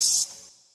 OPEN HAT - VIEWS.wav